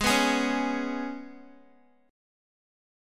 G#mbb5 chord